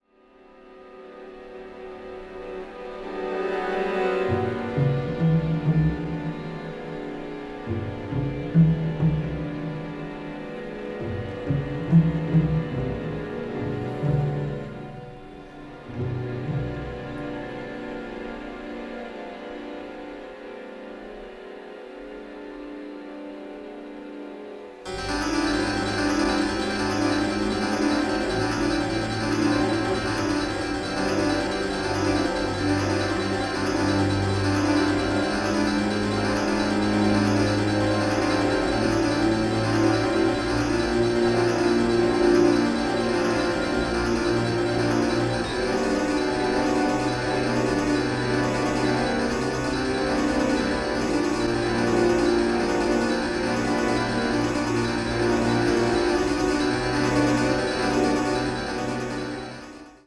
suspense writing for strings